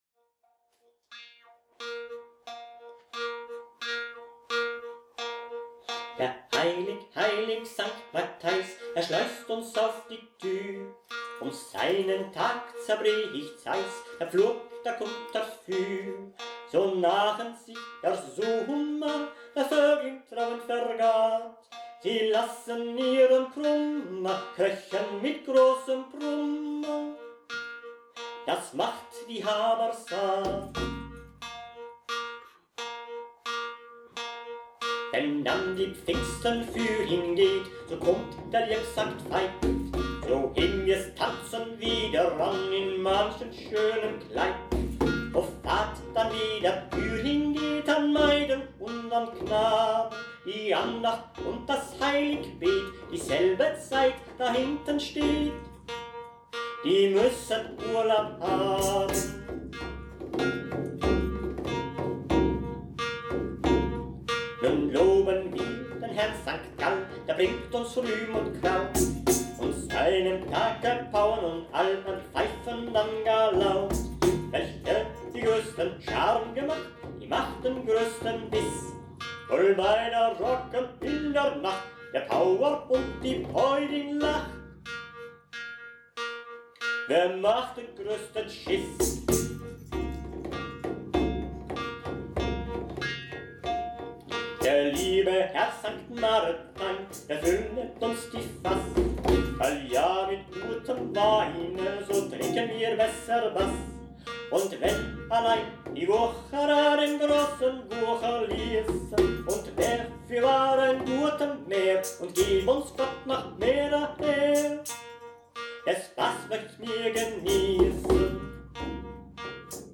Maultrommeln
Track 5,  Typ Bruck: Der heilig herr sanct Matheis (Anonym, ca. 1515)